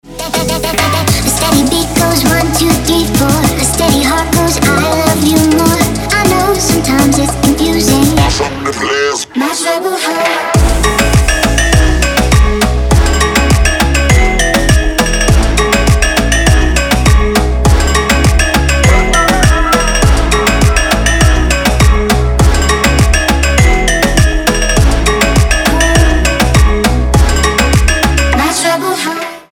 • Качество: 320, Stereo
атмосферные
Moombahton
забавный голос